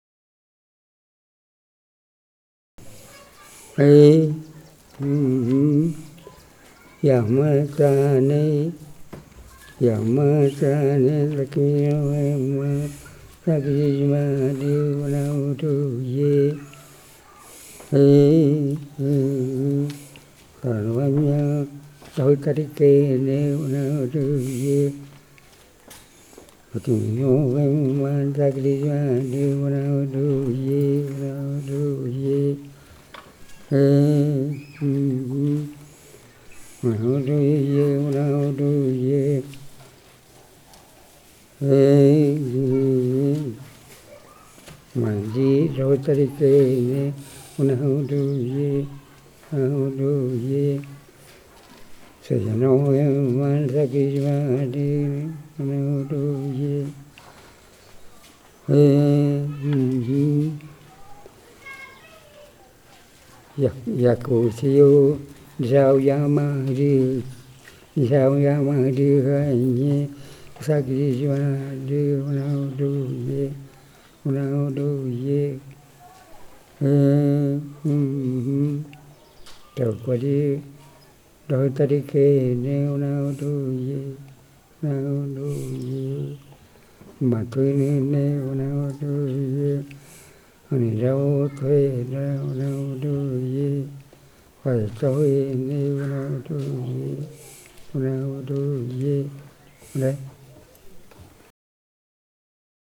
Povo Ye'kwana
Execução do motivo melódico do canto Wä’daka’jä ainho munu wötäädö yacchuumatoojo. acchudi.